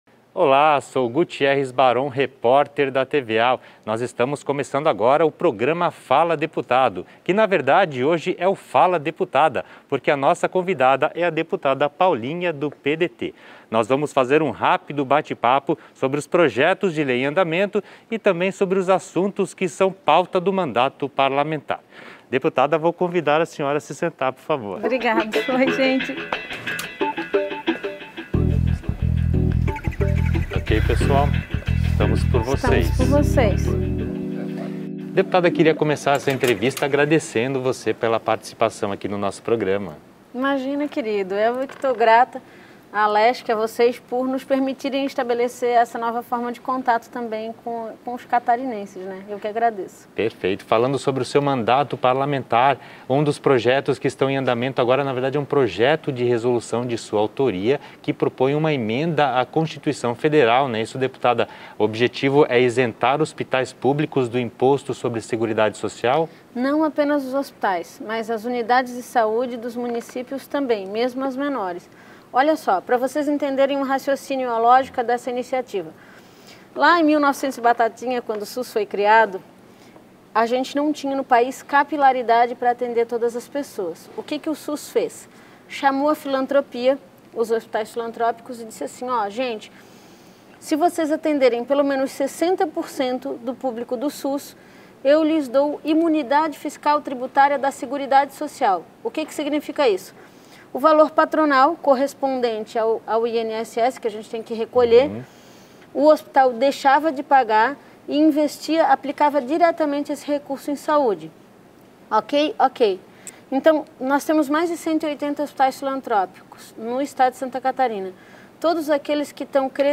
A deputada Paulinha (PDT) é a entrevistada do programa que traz um bate-papo sobre os projetos em tramitação na Casa e assuntos de interesse dos catarinenses.